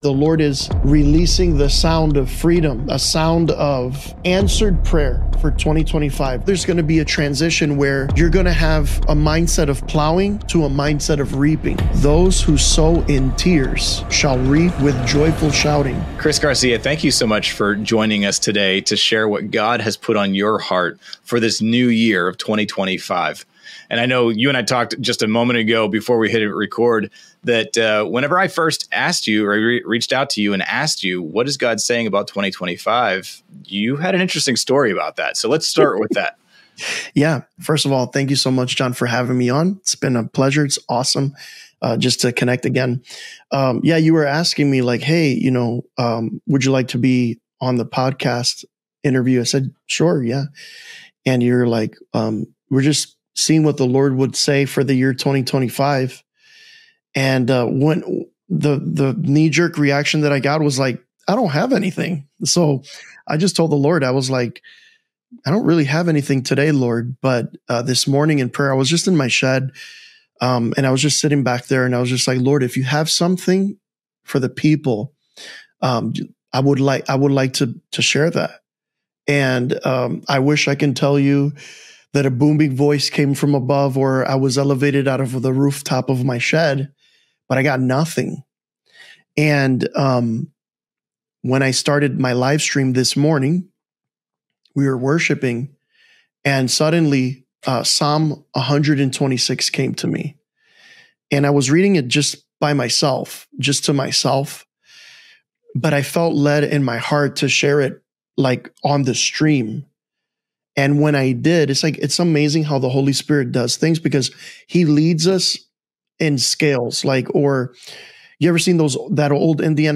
News stories and interviews well told from a Christian perspective.